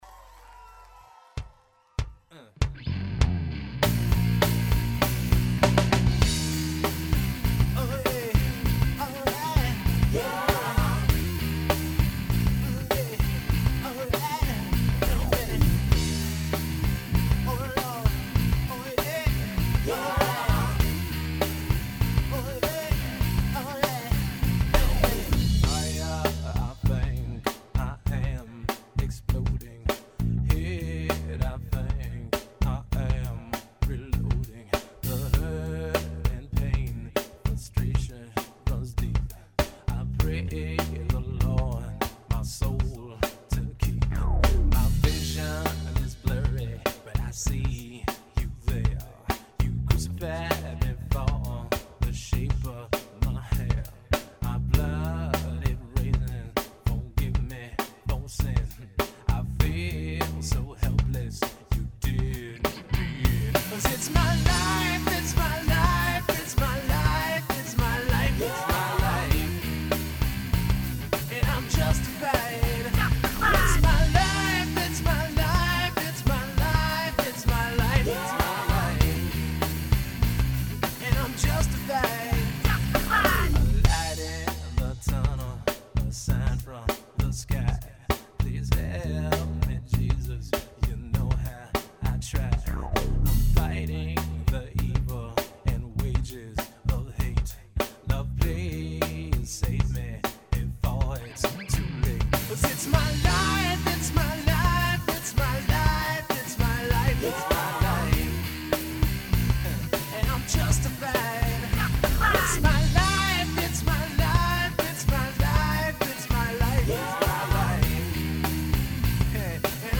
Hence all the crazy splices and sounds.